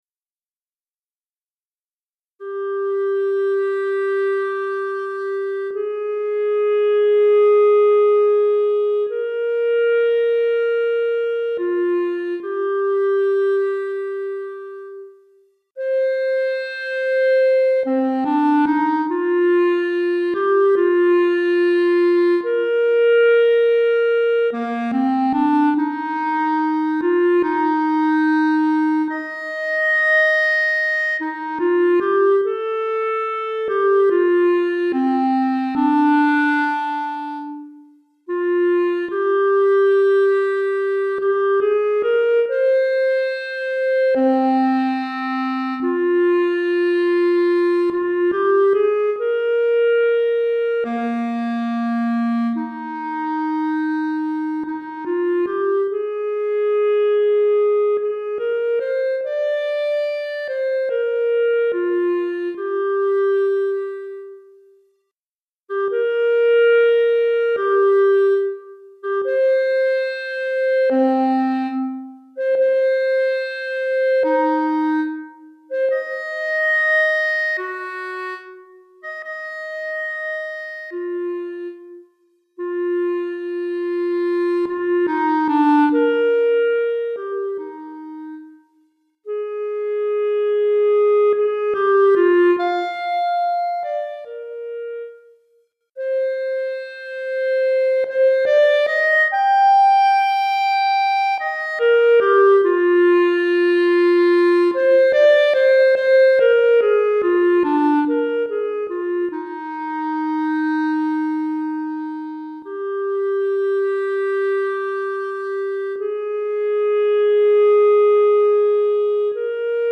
Clarinette Solo